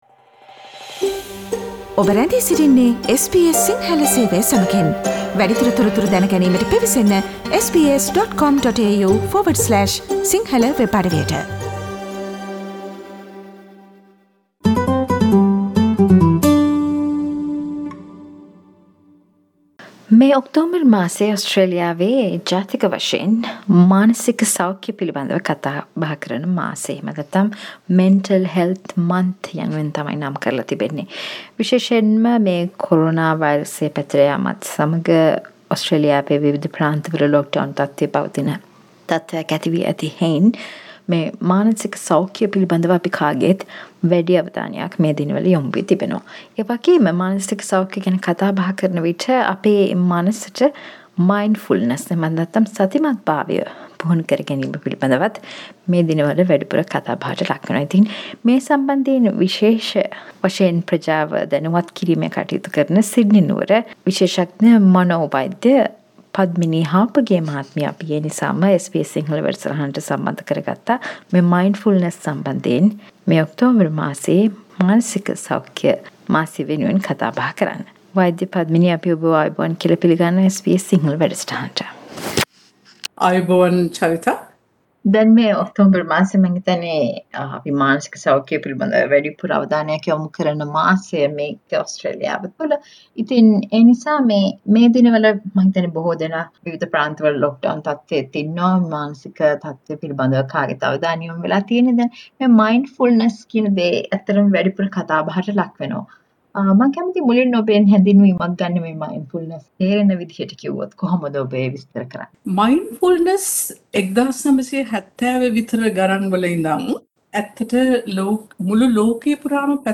SBS සිංහල සිදු කල සාකච්චාවට සවන් දෙන්න.